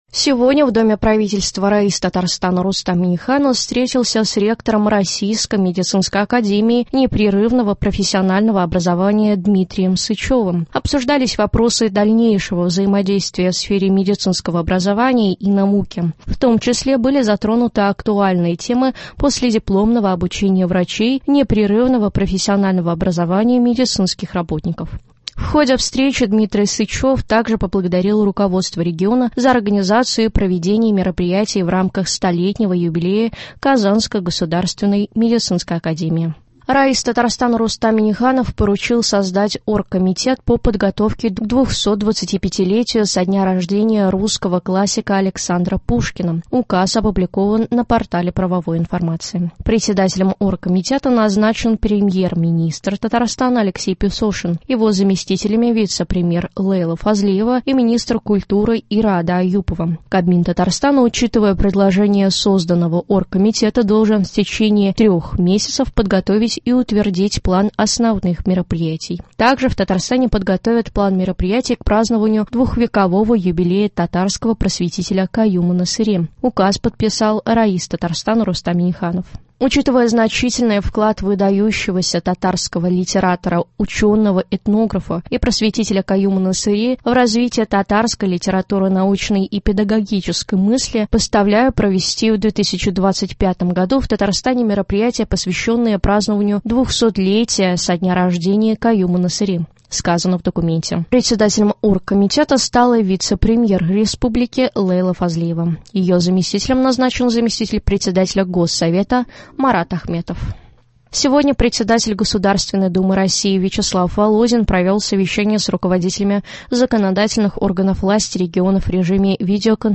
Новости (22.03.23)